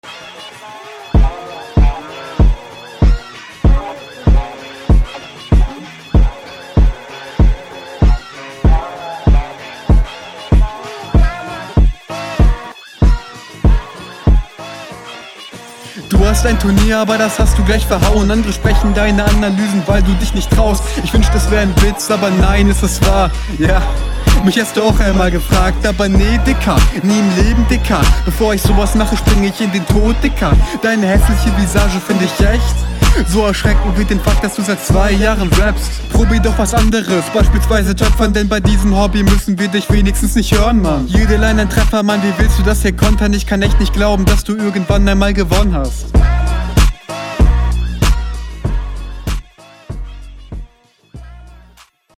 Joo cooler Beat auf jeden!